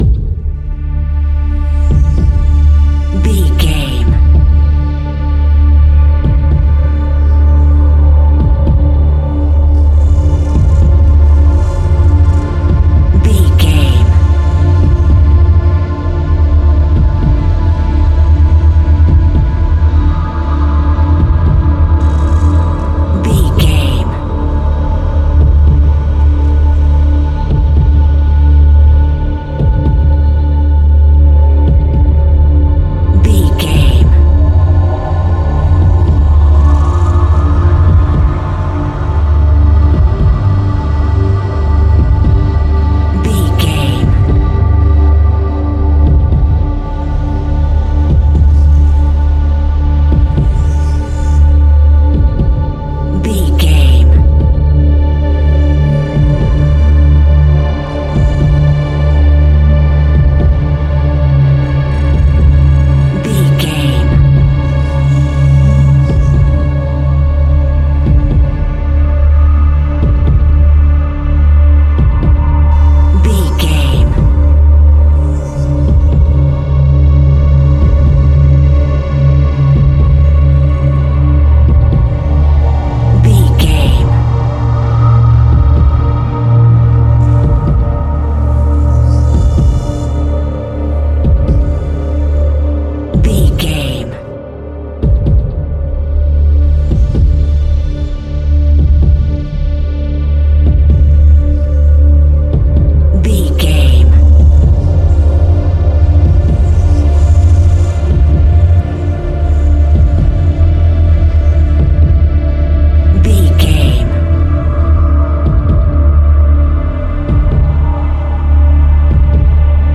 Scary Music for a Heartbeat.
In-crescendo
Thriller
Aeolian/Minor
ominous
dark
haunting
eerie
synthesiser
drums
strings
Synth Pads
atmospheres